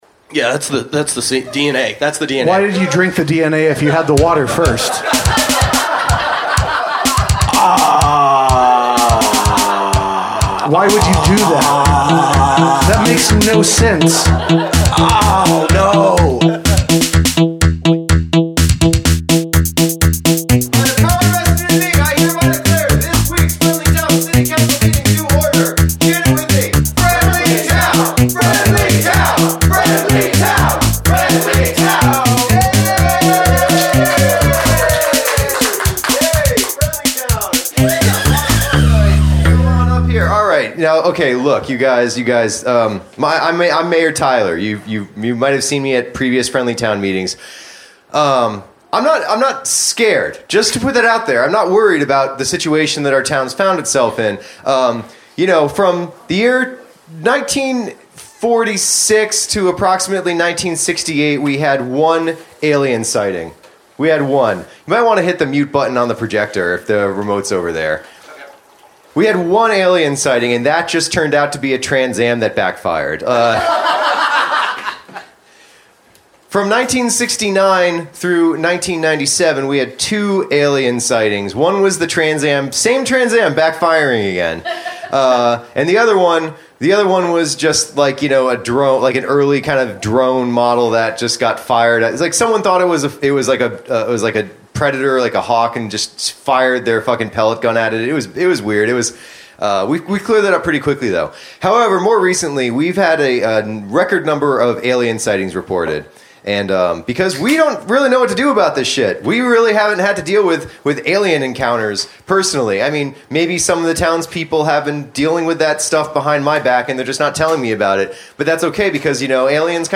Recorded Live at the Pilot Light September 27, 2017, Knoxville TN